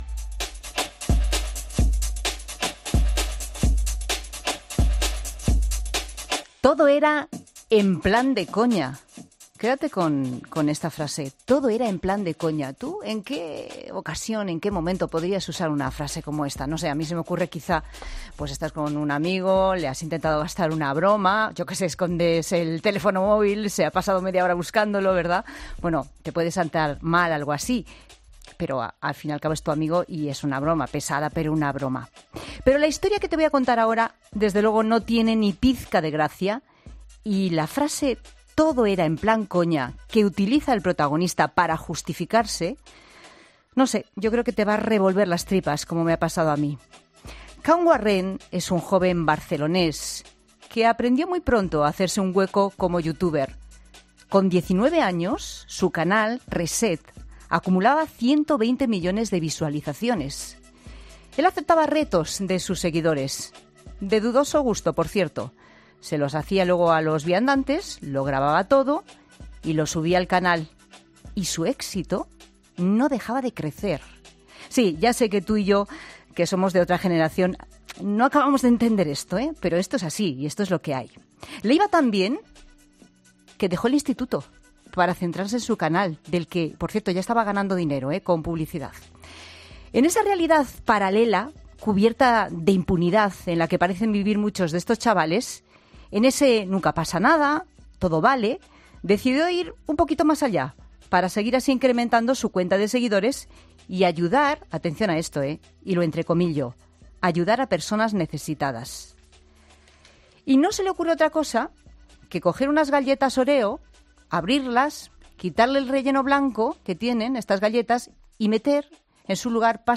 Miguel Ángel Aguilar, fiscal de delitos de odio de Barcelona, ha estado en 'La Tarde' de COPE para explicar más detalles sobre este caso